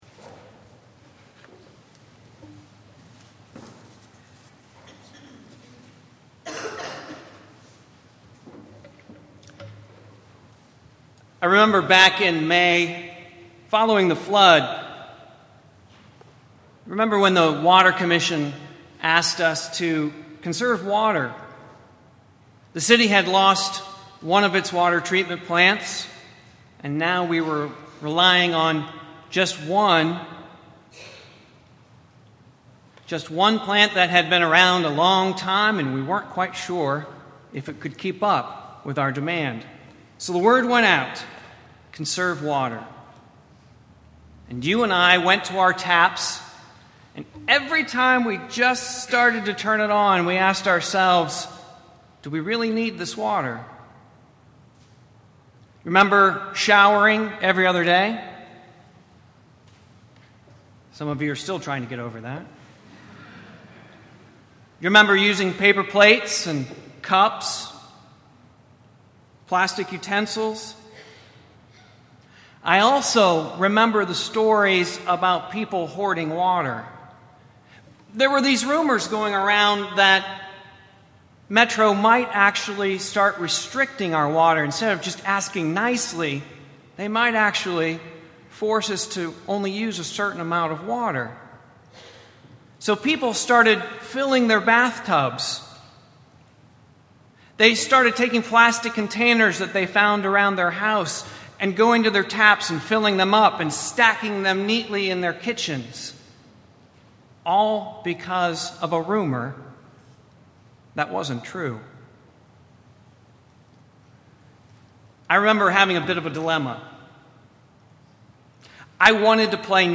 preached at Vine Street Christian Church on August 1, 2010.